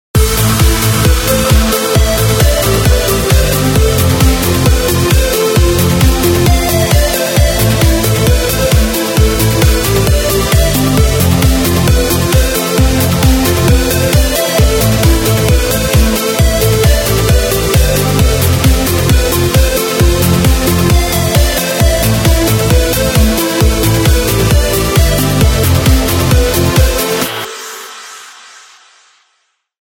Клубные [95]